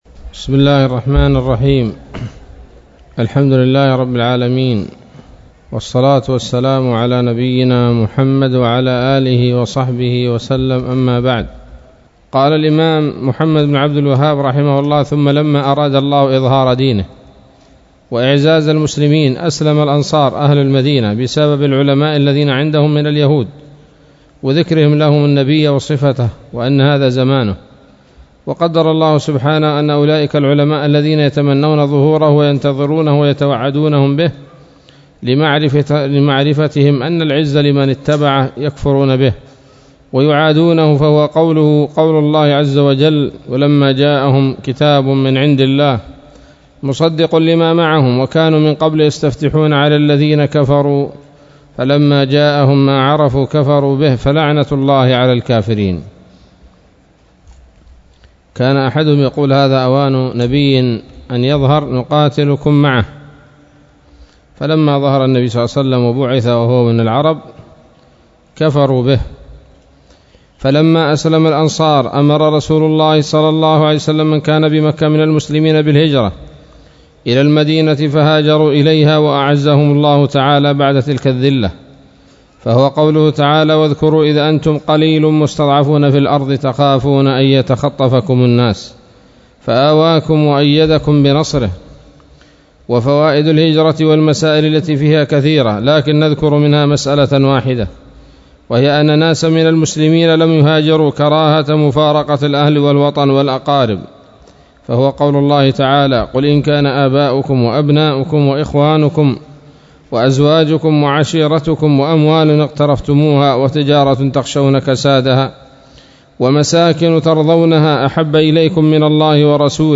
الدرس السابع من مختصر سيرة الرسول صلى الله عليه وسلم